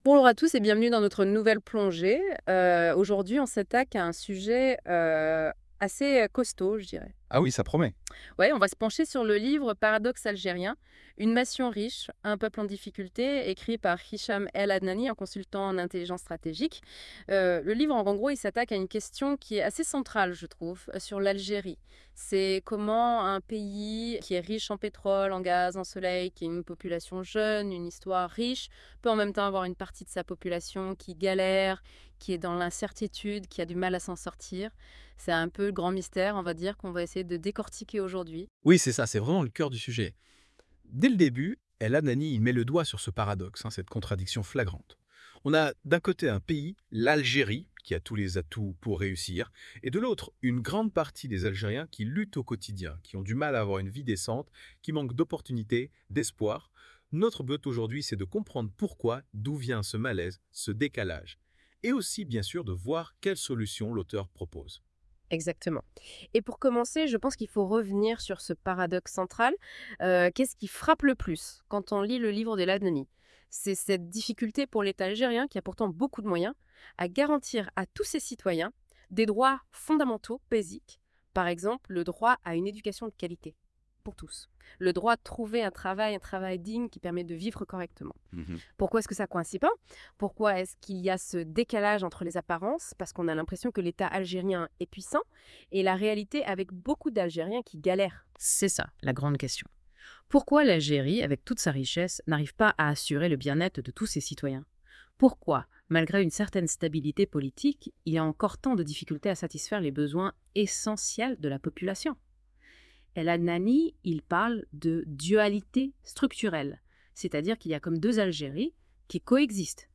Débat (61.12 Mo) 1. Quel est le paradoxe fondamental que l'ouvrage met en lumière concernant l'Algérie ? 2.